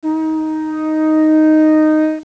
horn2.wav